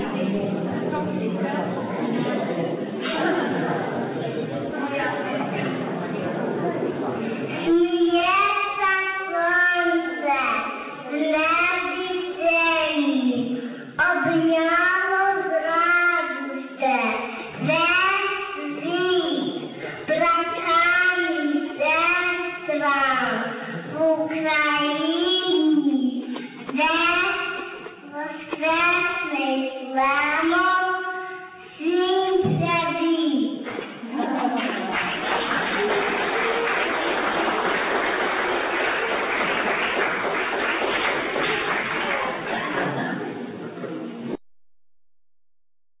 Після вечері та свяченого відбулися виступи дітей та молоді, а також загальна молитва.